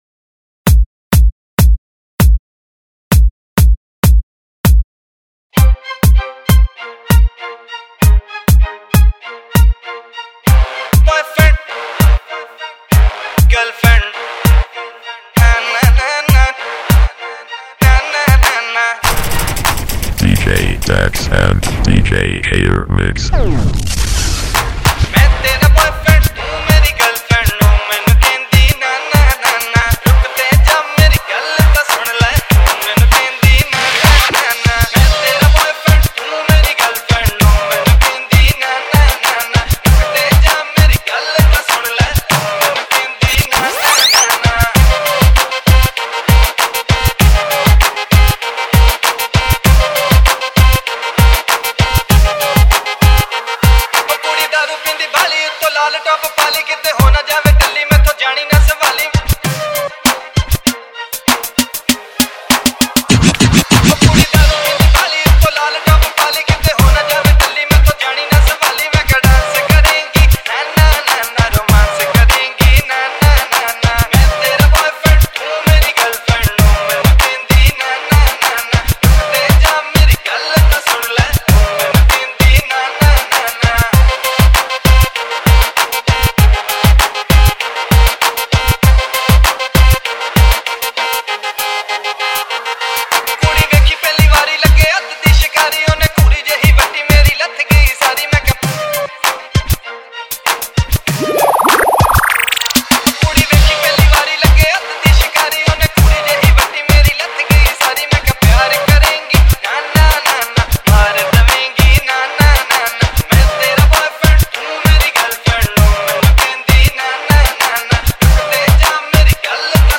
DJ Remix